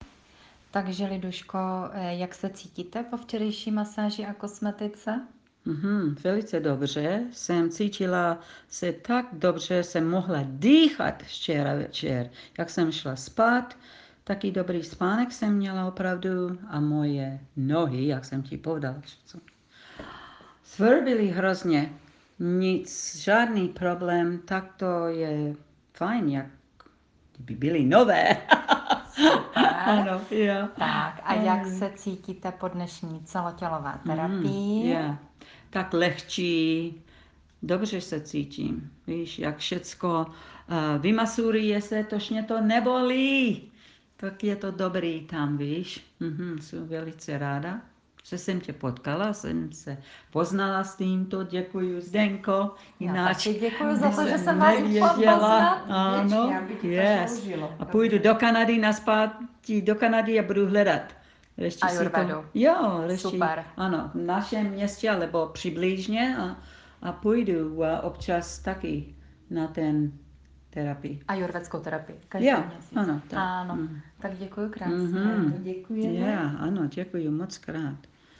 Své pocity po ajurvédské masáži popsala jedna z klientek.